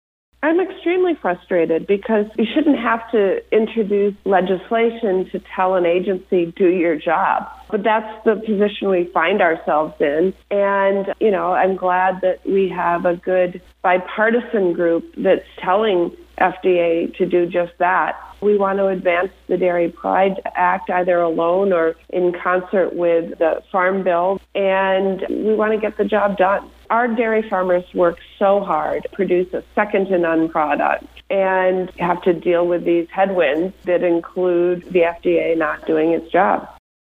Audio with Senator Tammy Baldwin (D-WI)